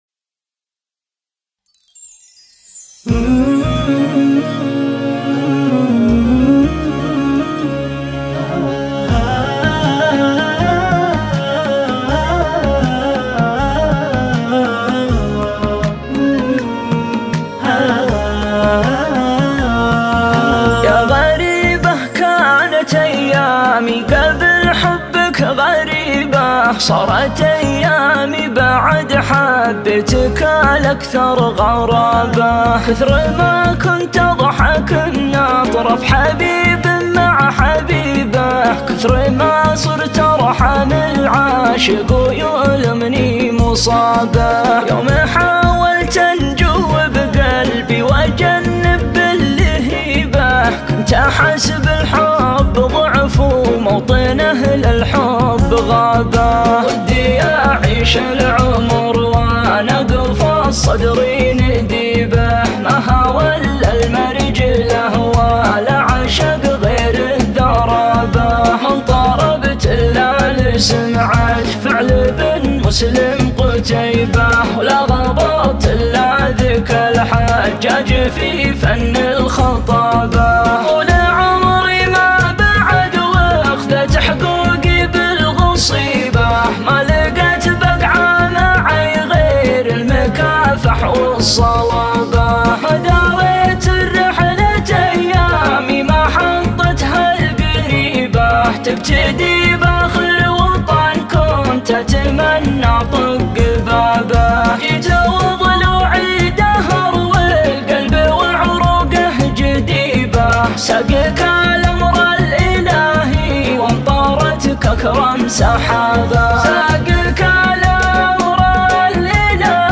شيلات حزينة